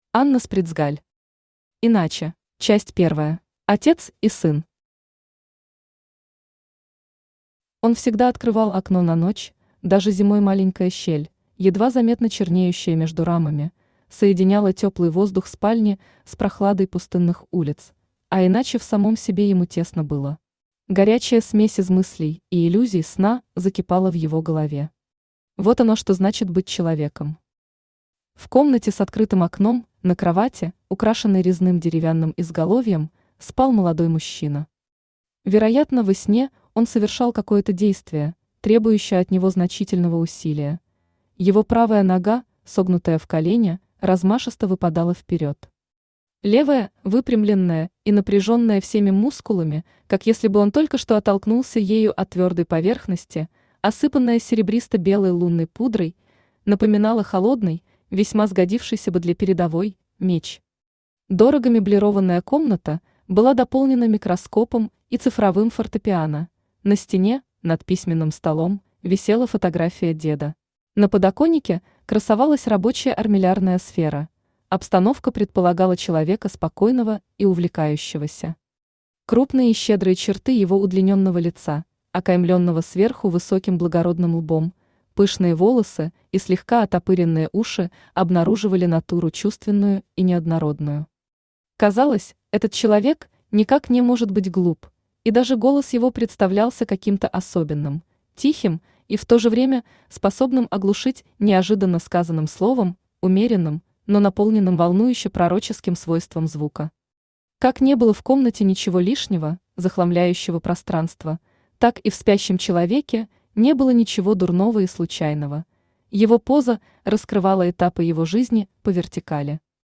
Аудиокнига Иначе | Библиотека аудиокниг
Читает аудиокнигу Авточтец ЛитРес.